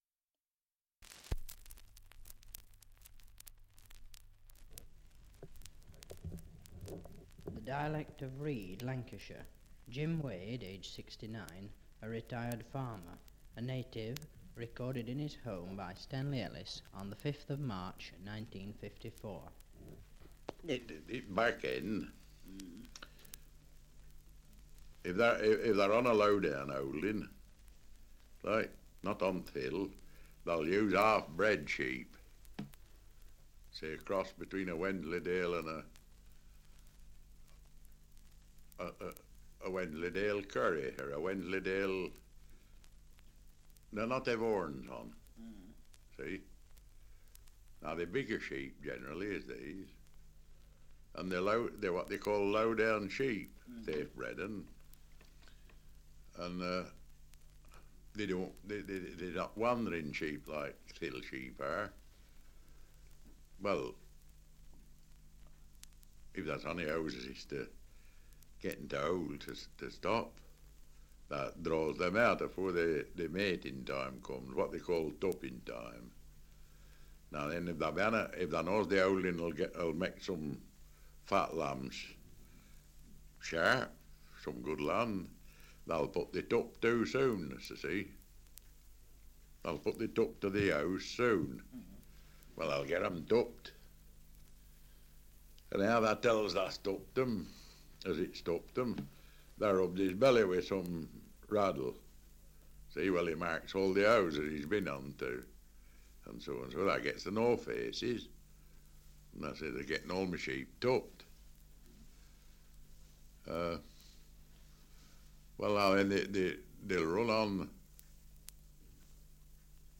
Survey of English Dialects recording in Read, Lancashire
78 r.p.m., cellulose nitrate on aluminium